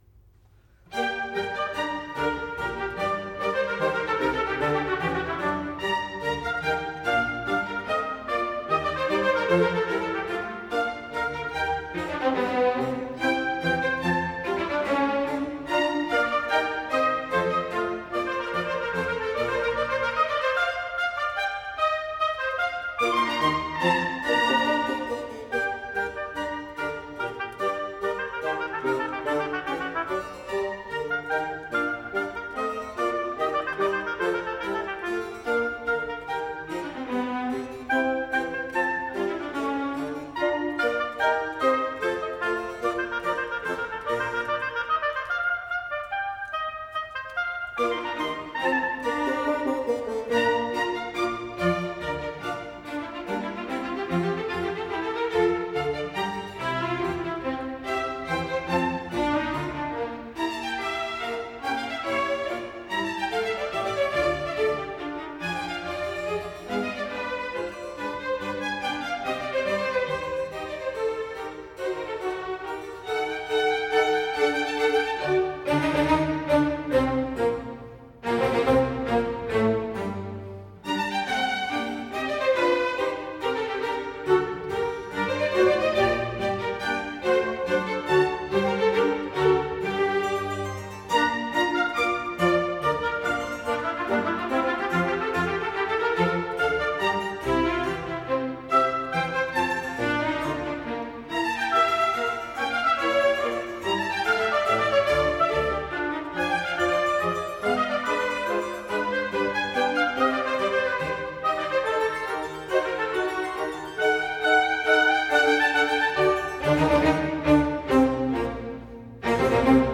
G minor - Allegro